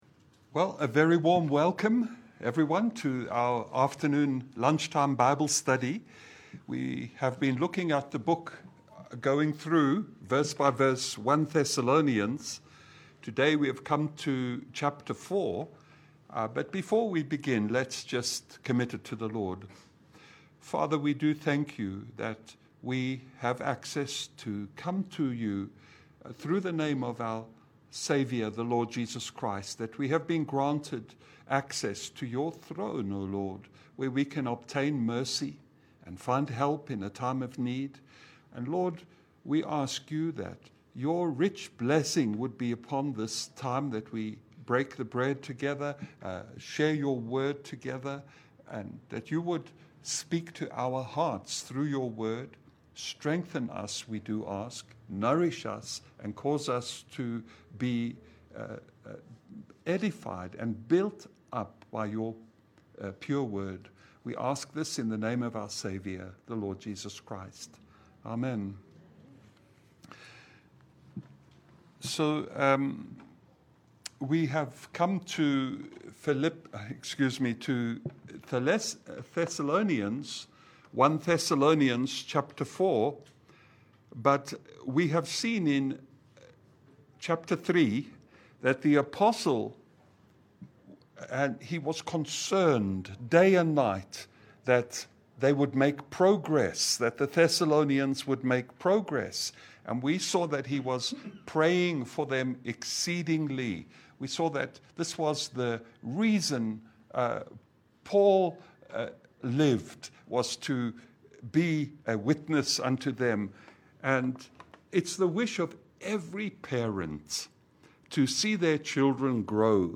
Service Type: Lunch hour Bible Study